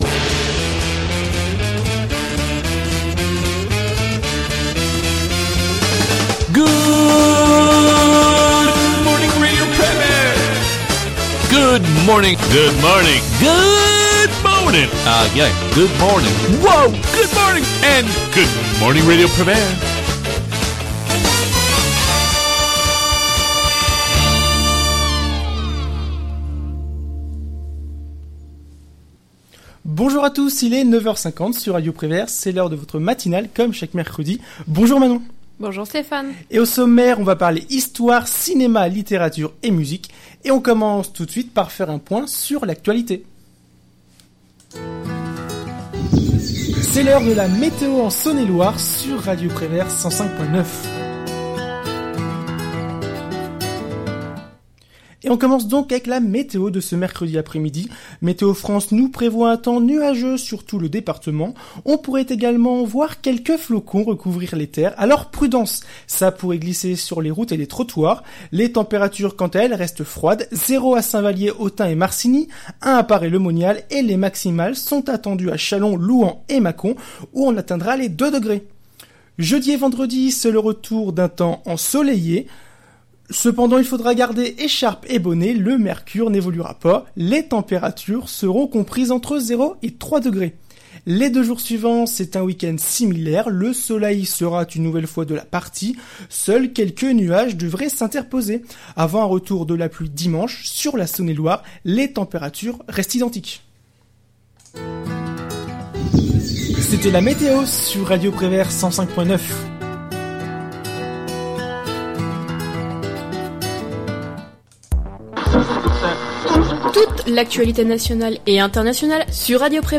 matinale9direct.mp3